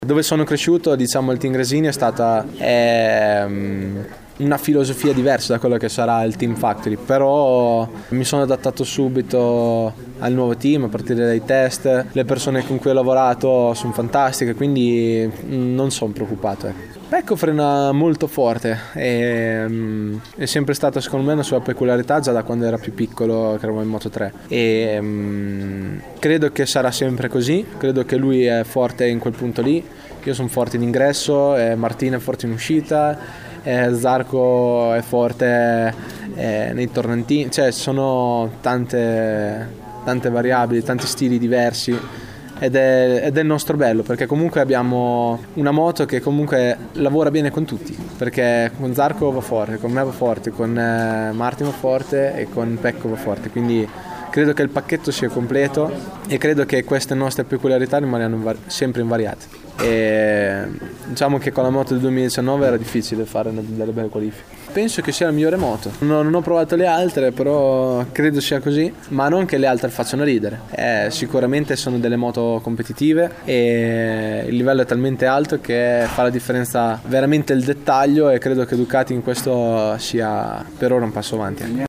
L’intervista a Enea Bastianini